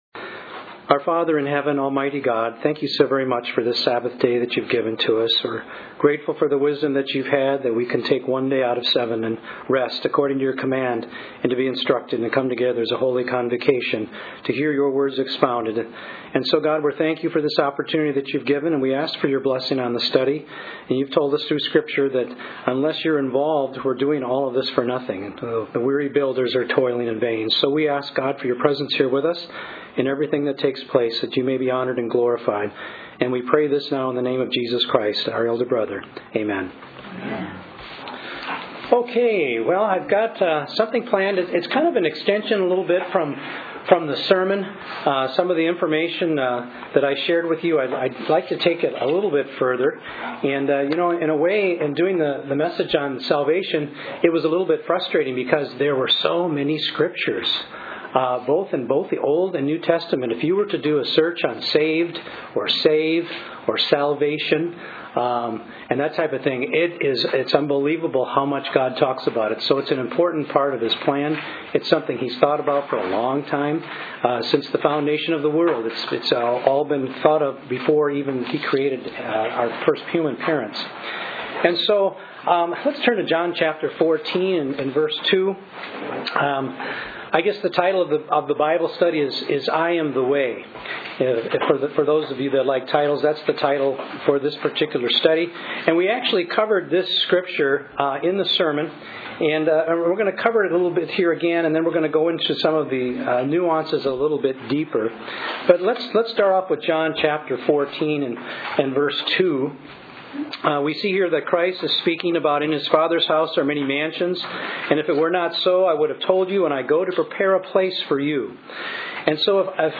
A Bible study on John 14:6